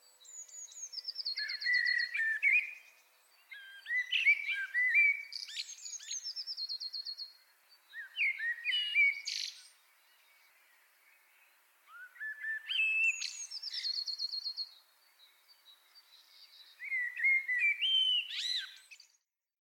birds_short_x.mp3